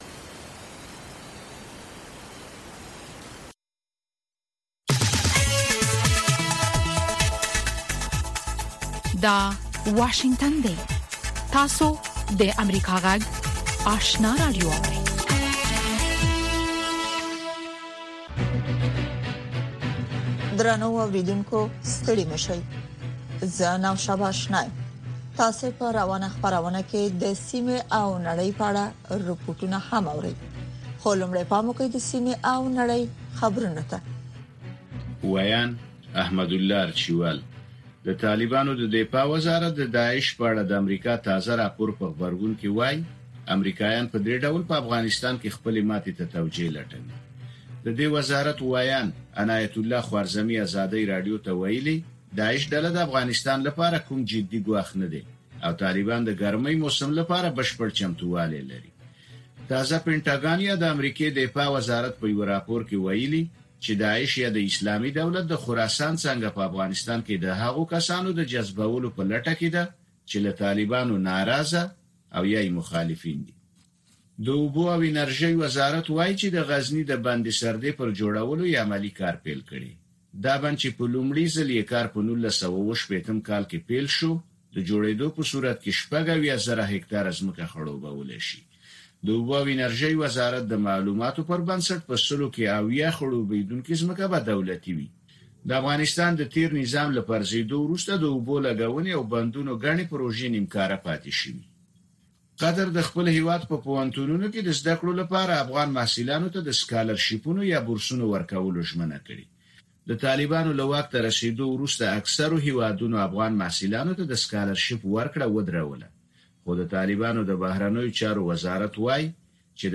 لومړنۍ ماښامنۍ خبري خپرونه